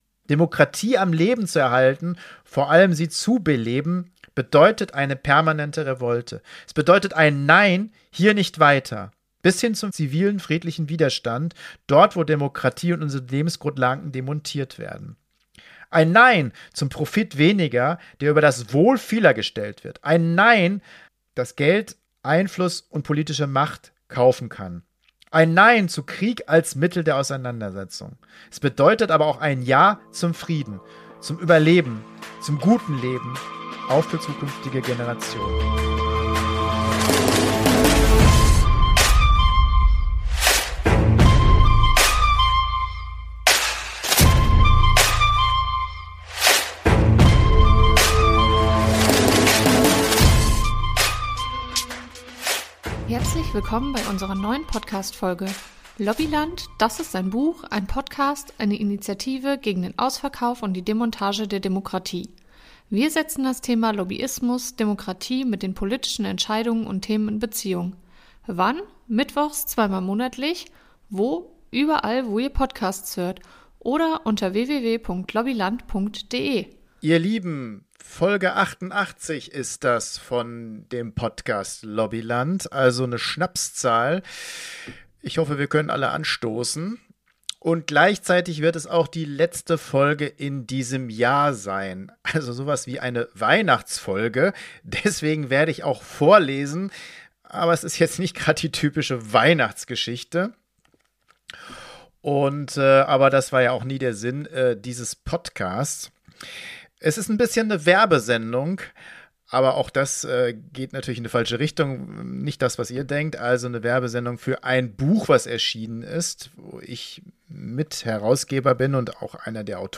Die doch deutlich andere Weihnachtslesung.